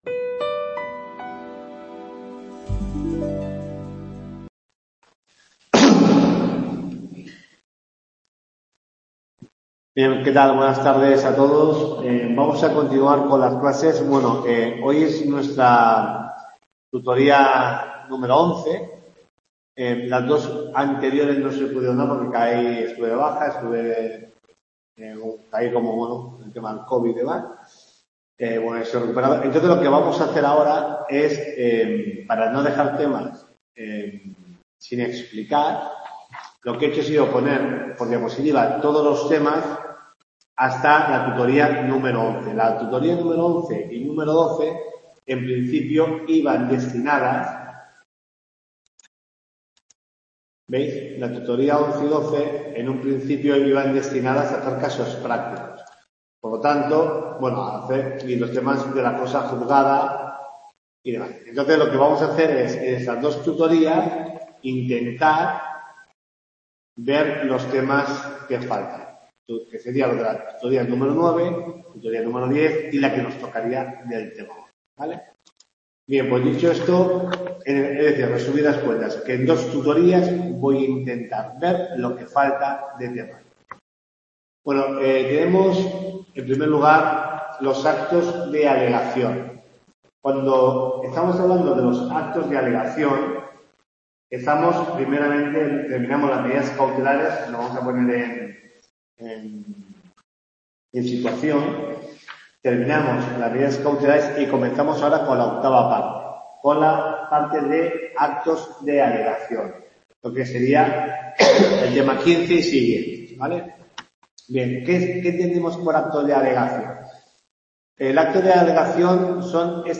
TUTORIA 9